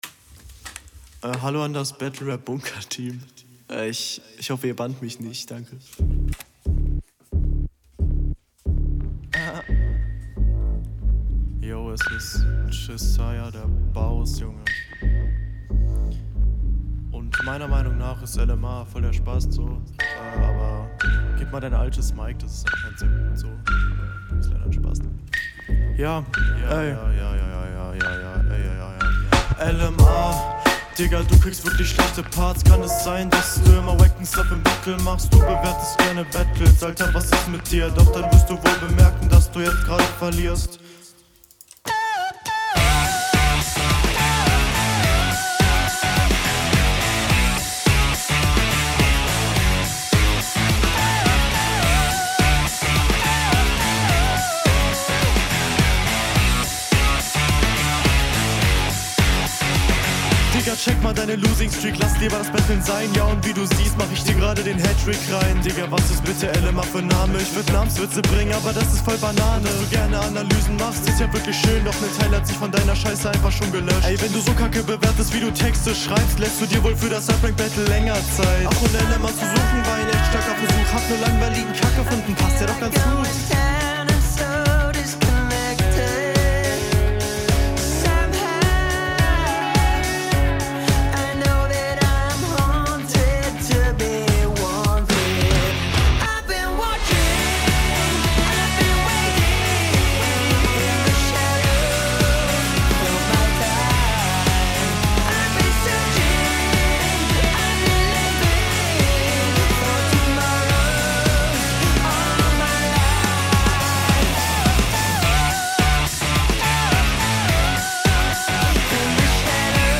Flow: Sehr guter Flow, bisschen drucklos aber passt dementsprechend auf den ersten Beat.
Flow: Nicer chilliger Flow. Patterns sind stabil.